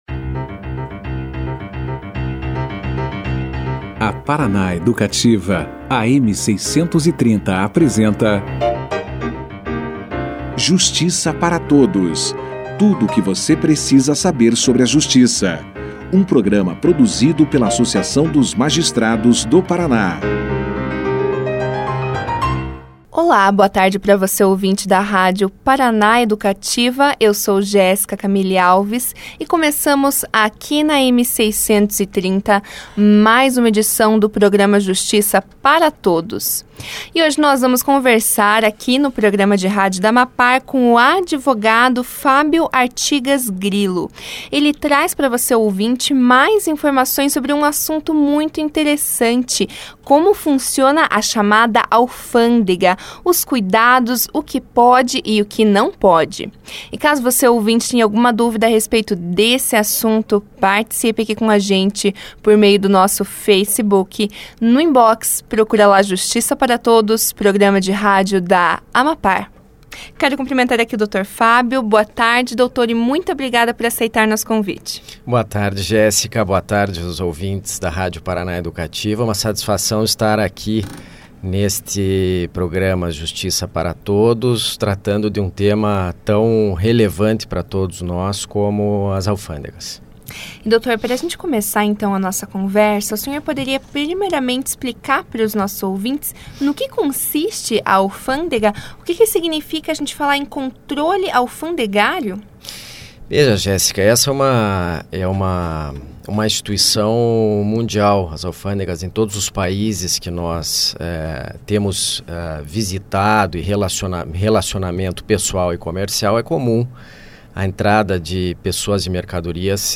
Na entrevista, o convidado esclareceu ainda o que pode e o que não pode ser trazido em uma viagem internacional.